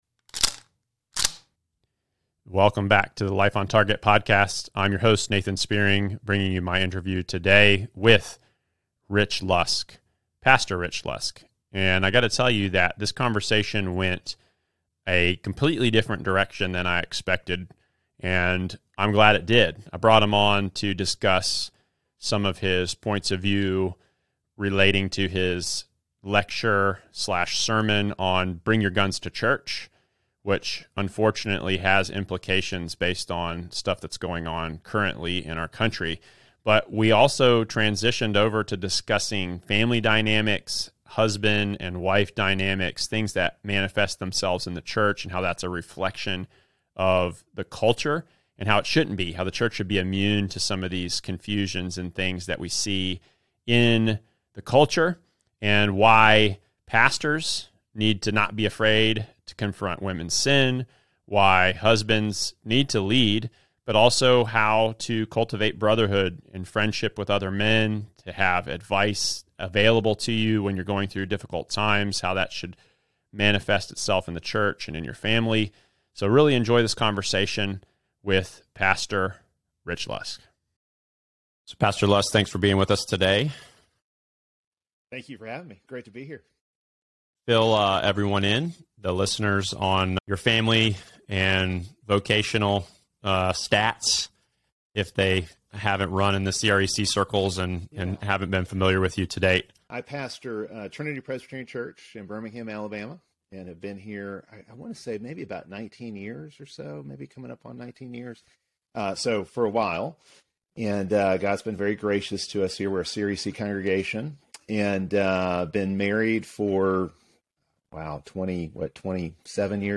Men and women rise or fall together. It is a dance that God has wired into the created order. In this week’s conversation